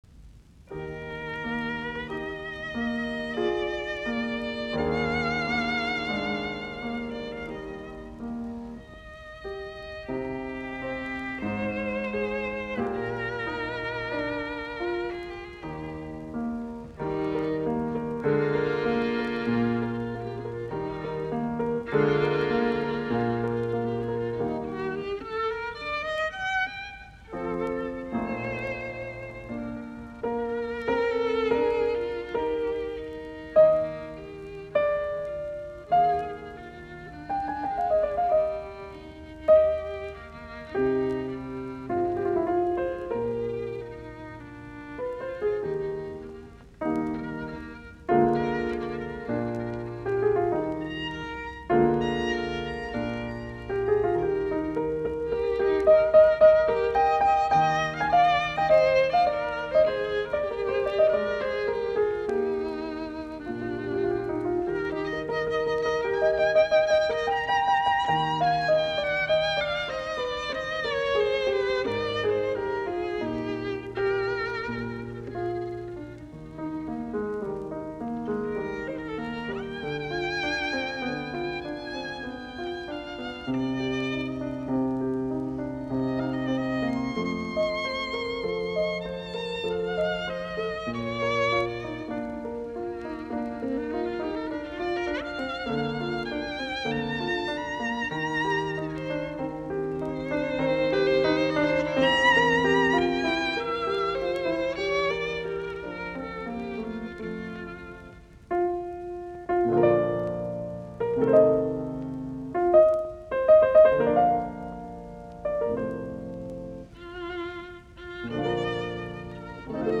viulu, piano